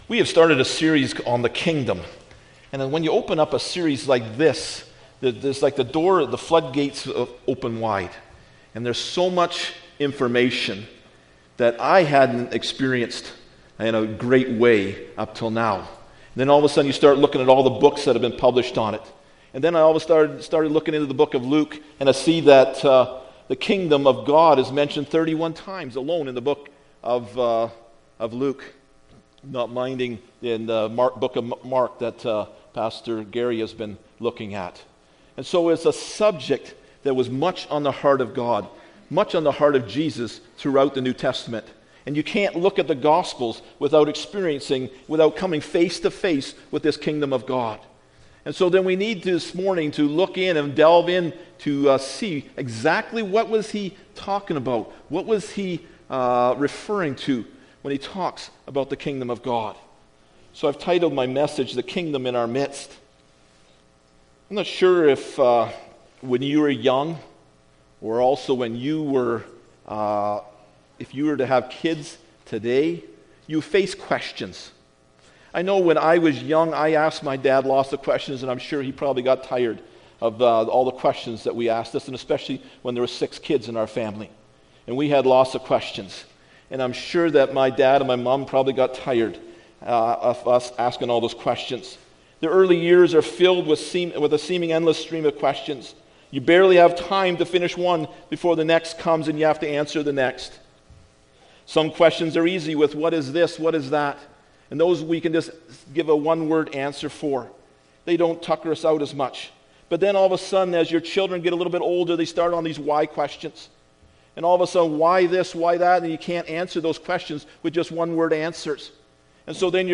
The Kingdom of God Service Type: Sunday Morning Preacher